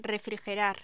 Locución: Refrigerar
Sonidos: Voz humana